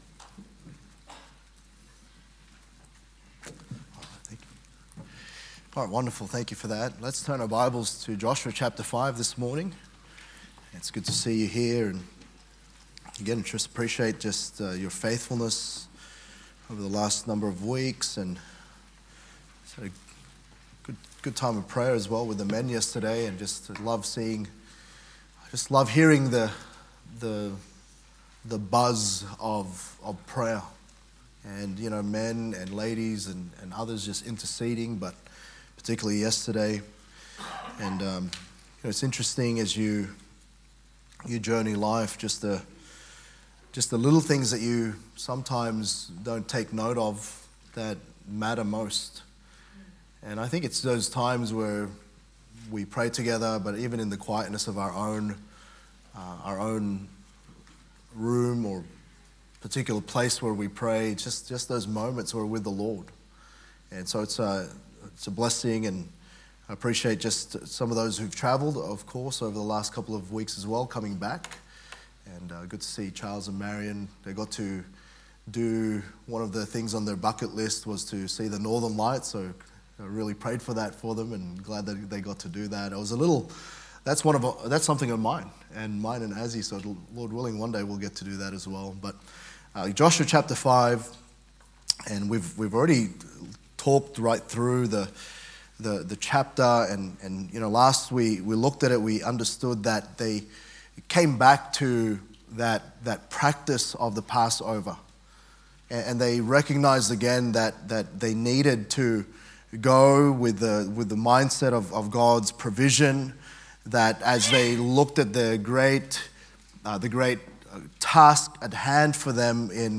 Sun AM